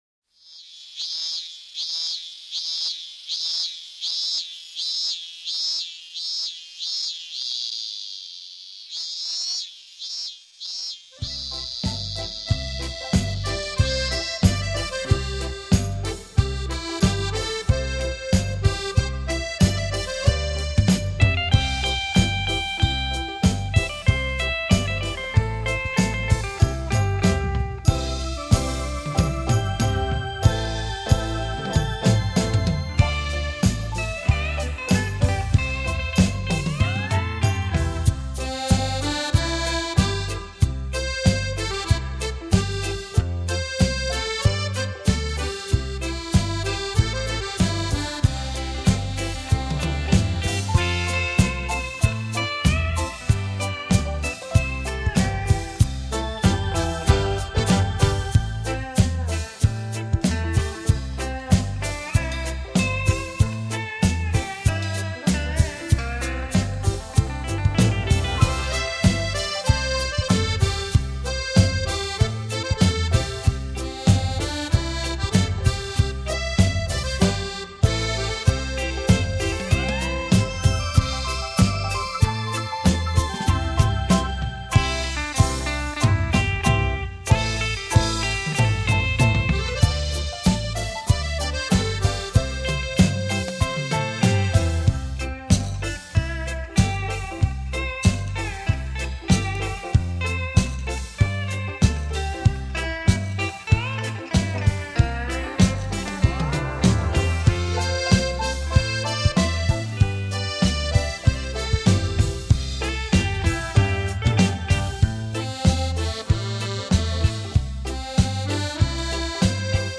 大乐团与效果音组合
主奏手风琴，电脑鼓、电吉它、电子琴及打击乐器组合伴奏，节奏清新明快，曲目首首动人。